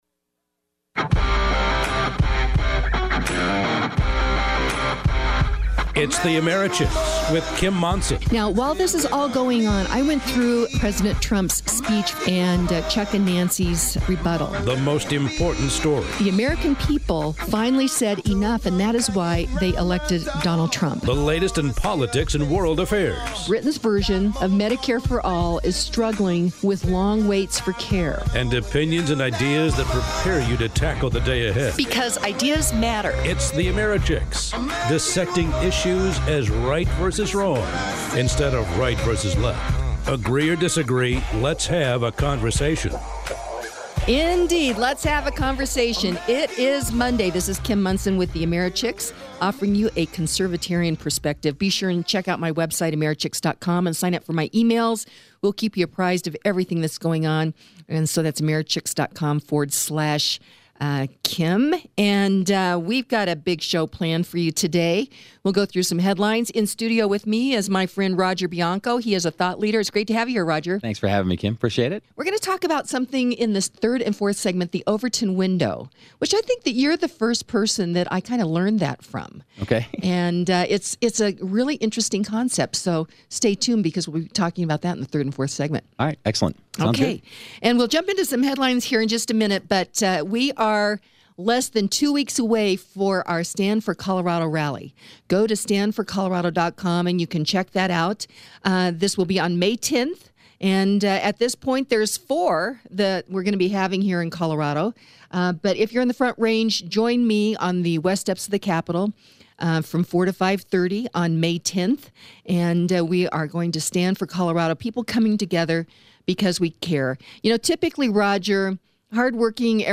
guest co-host